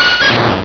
Cri_0237_DP.ogg